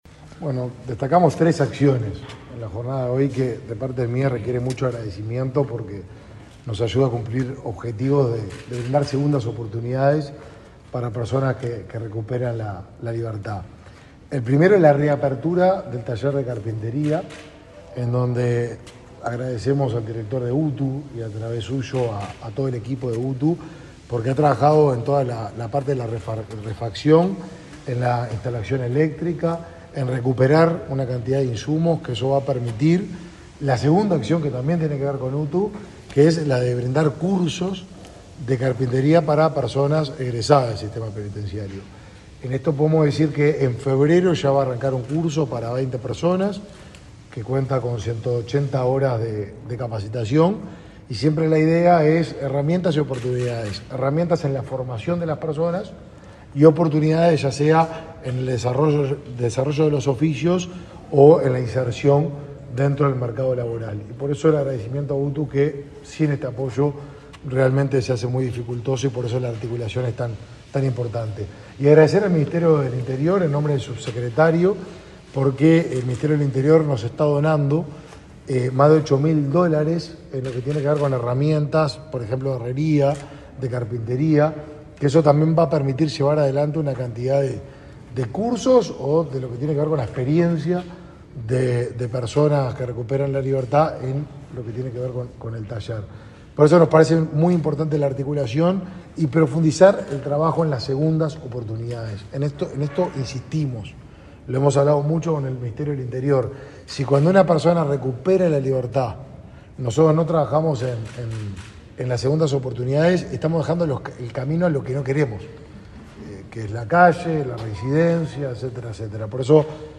Declaraciones a la prensa del ministro Martín Lema
El ministro de Desarrollo Social, Martín Lema, dialogó con la prensa antes de participar en el acto de reapertura del taller de carpintería de la